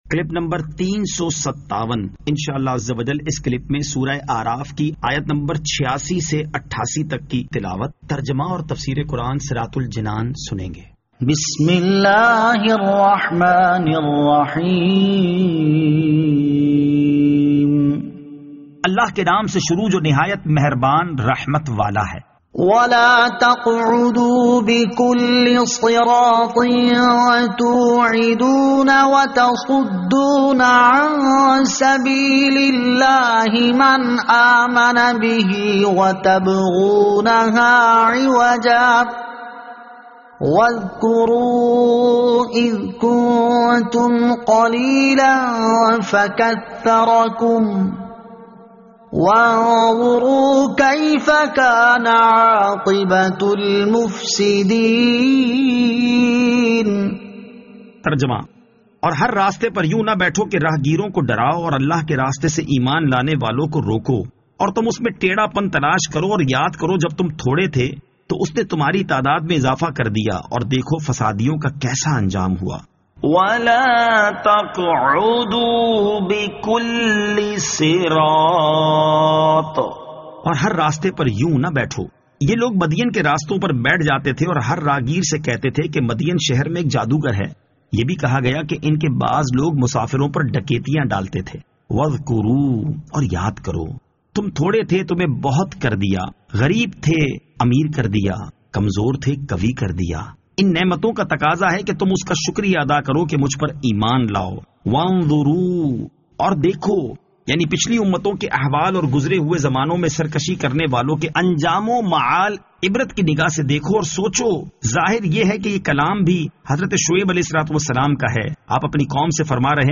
Surah Al-A'raf Ayat 86 To 88 Tilawat , Tarjama , Tafseer